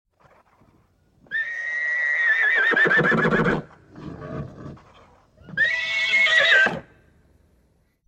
Kůň
Krásně řehtá: „Ihahaha!“